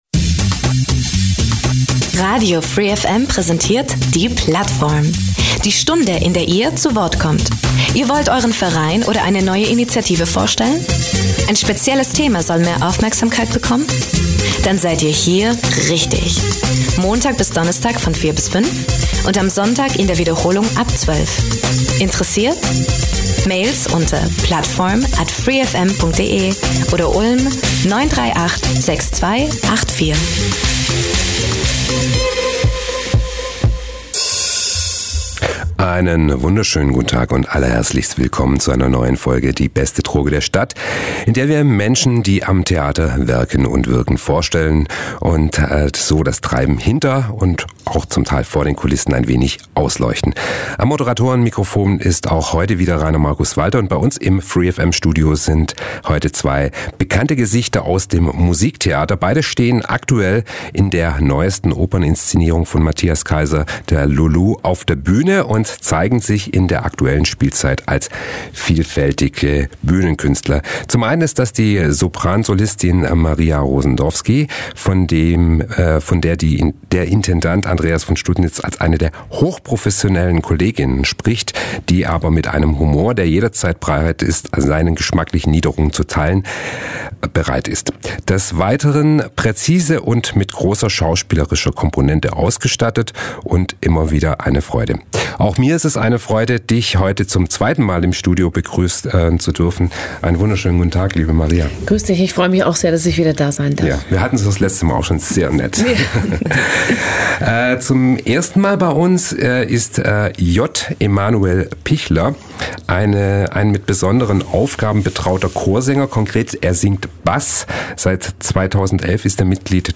Heute waren zwei bekannte Gesichter aus dem Musiktheater in der Plattform zu Gast